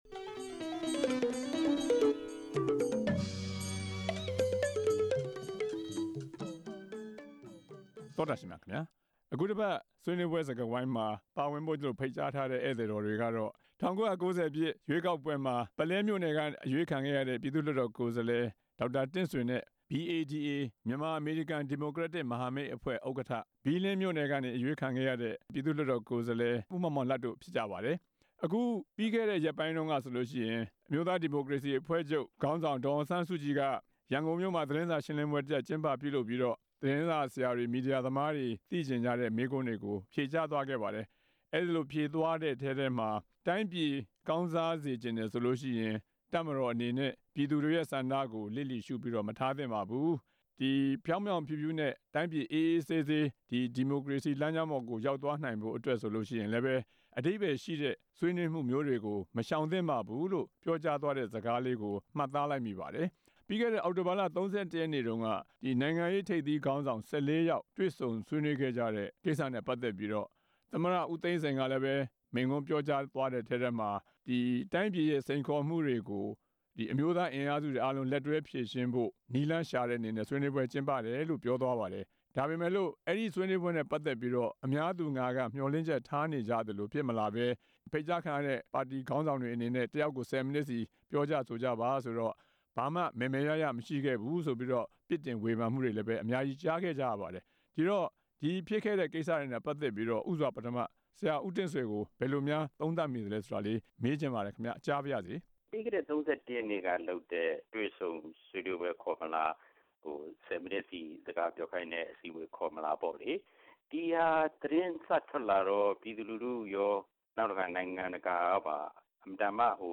သမ္မတနဲ့ ဒေါ်အောင်ဆန်းစုကြည်တို့ရဲ့ ပြောစကားတွေကို ဆွေးနွေးချက်